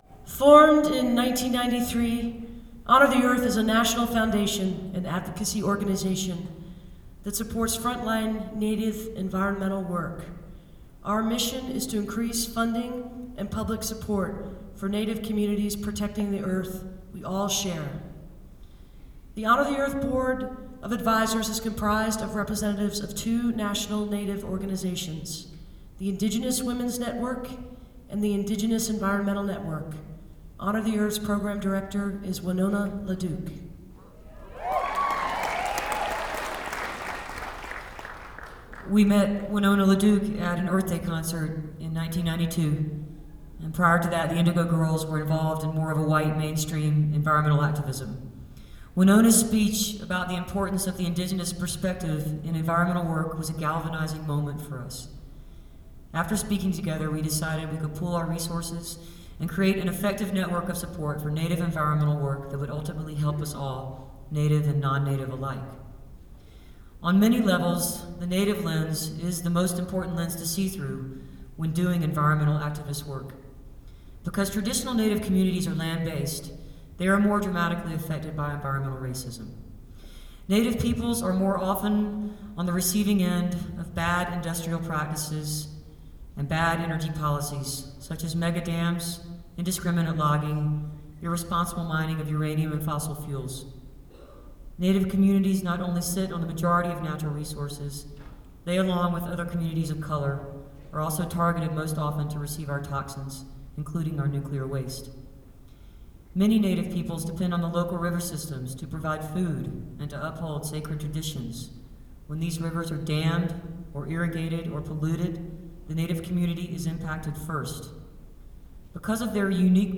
lifeblood: bootlegs: 2003-04-16: mazama auditorium (central oregon community college) - bend, oregon (honor the earth benefit with winona laduke)
03. comments by emily and amy (6:22)